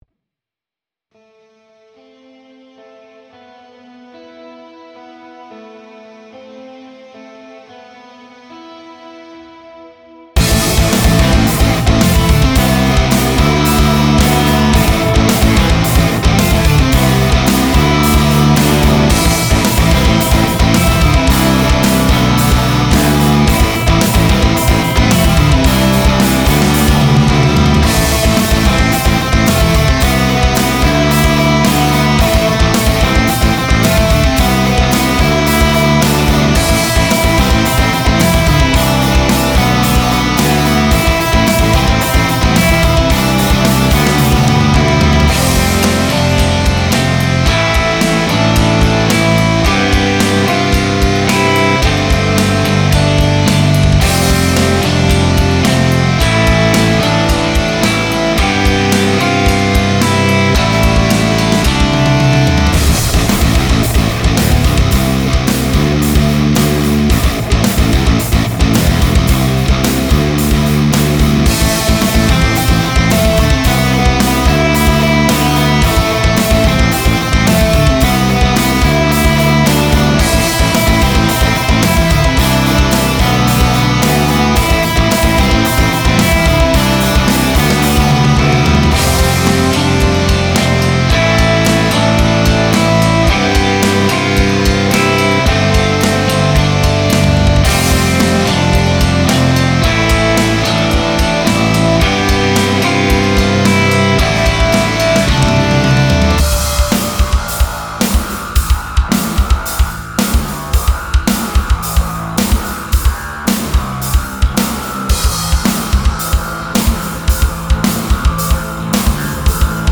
Genre: ambient progressive